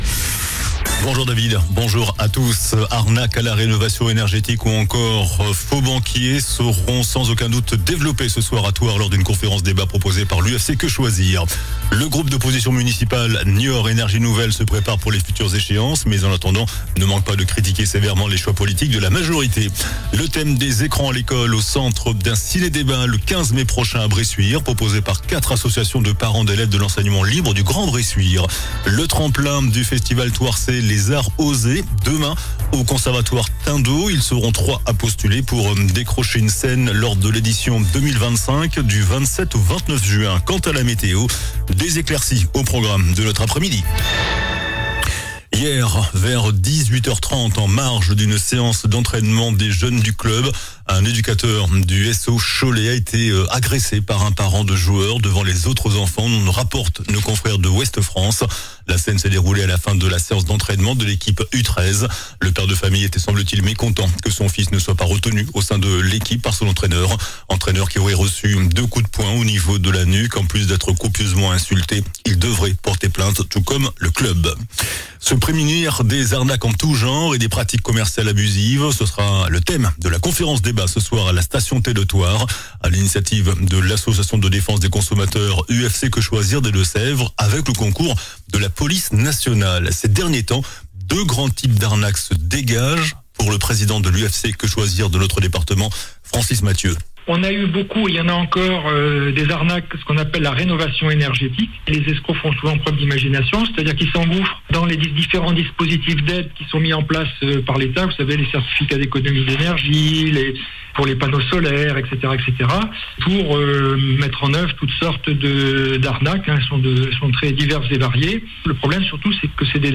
JOURNAL DU MARDI 06 MAI ( MIDI )